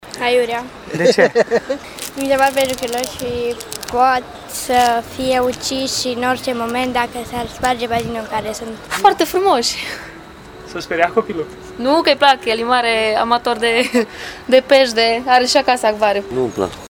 Timișorenii care au vizitat expoziția, vineri, spun că ideea este inedită, însă părerile au fost împărțite.
voxuri-rechini.mp3